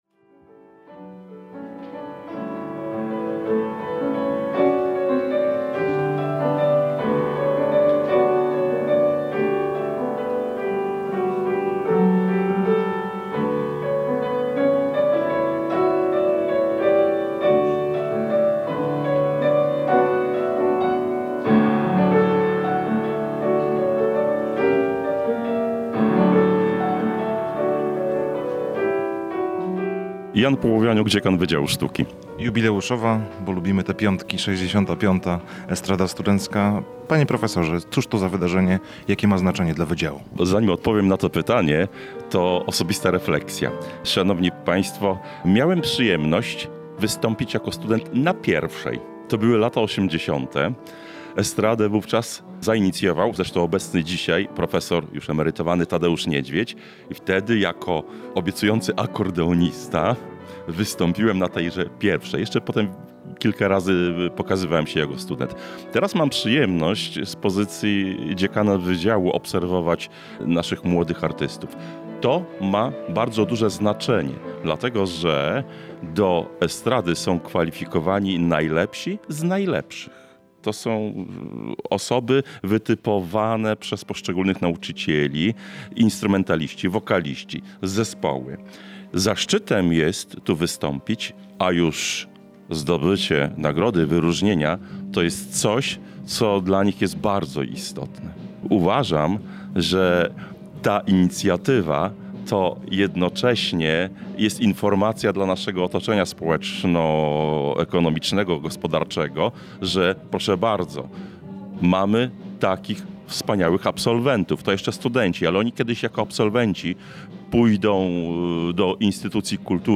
We wtorek 20 stycznia na Wydziale Sztuki zaprezentowali się studenci kierunku muzyka i estrada.